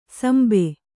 ♪ sambe